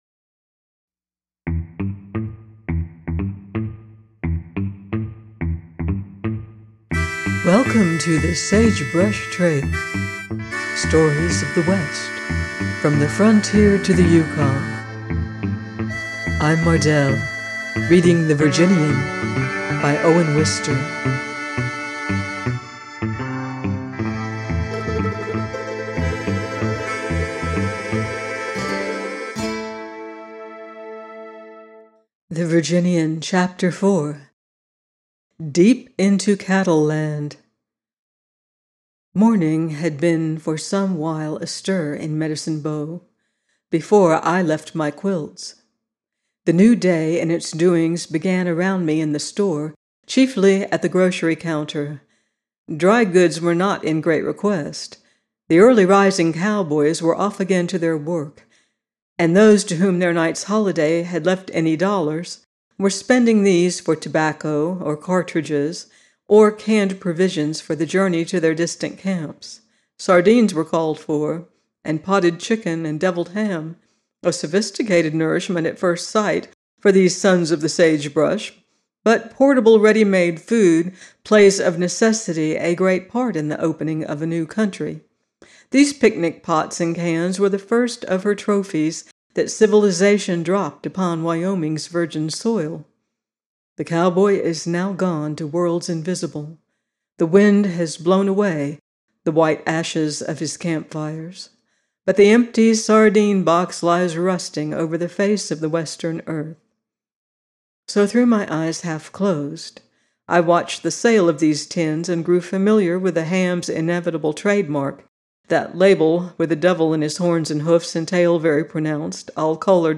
The Virginian 04 - by Owen Wister - audiobook